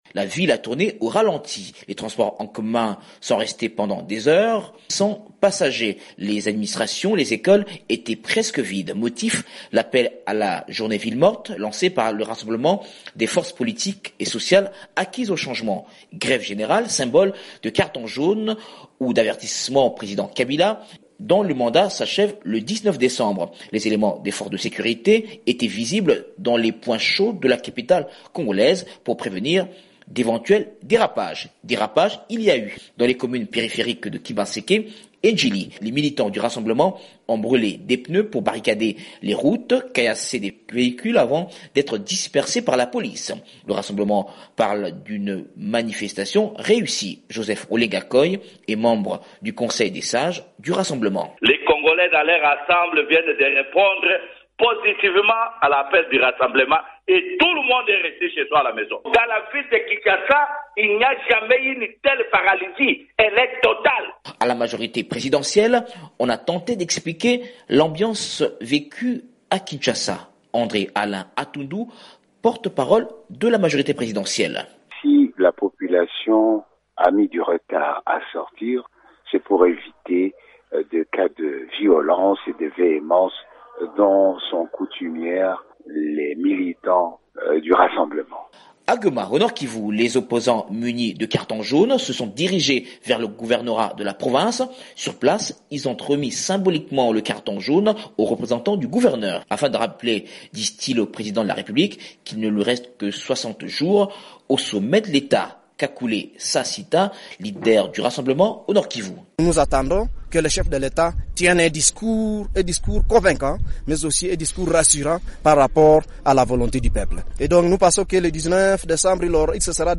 Le point sur la ville morte appelée par l’opposition en RDC/Reportage